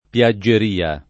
piaggeria [ p L a JJ er & a ] s. f.